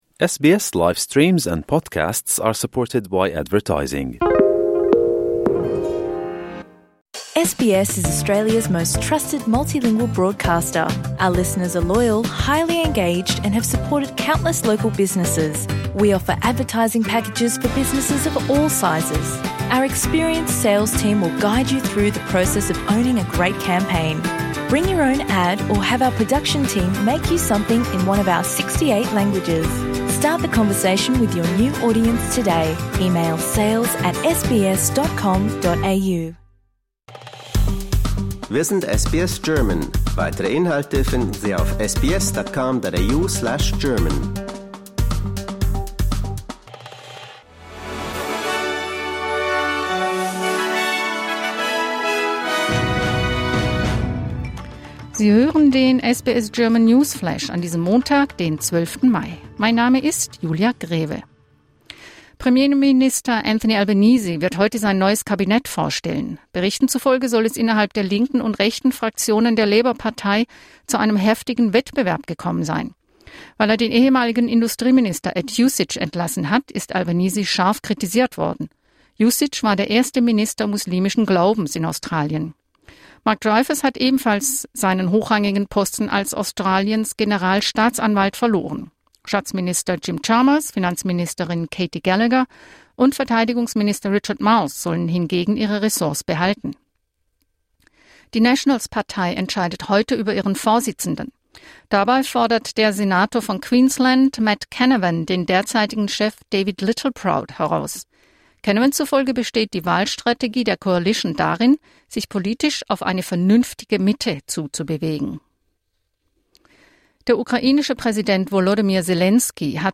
Meldungen des Tages, Montag den 12.05.25